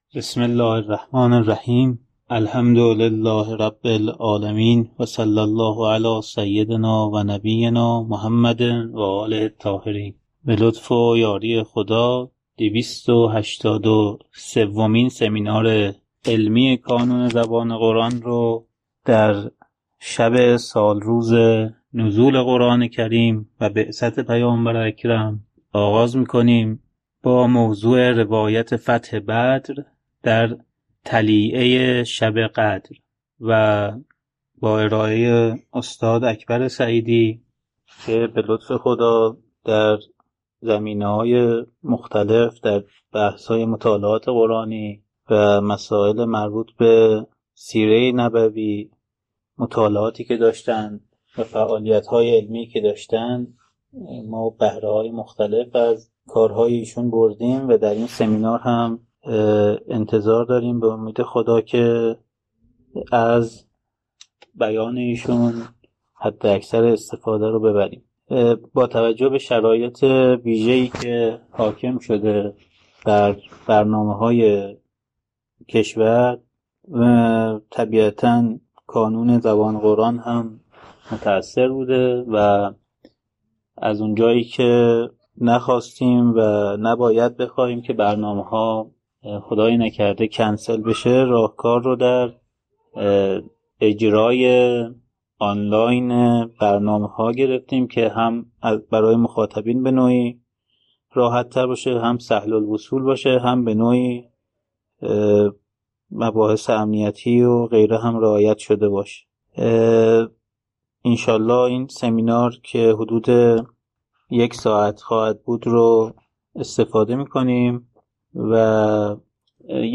روایت فتح بدر در طلیعۀ شب قدر: 283 سمینار علمی